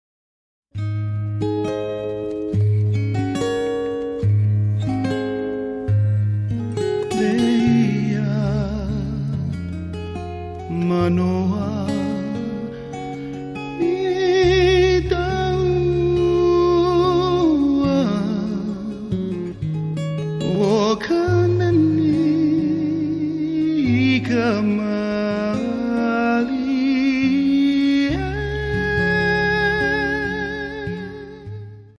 Genre: Traditional and contemporary Hawaiian.
guitarist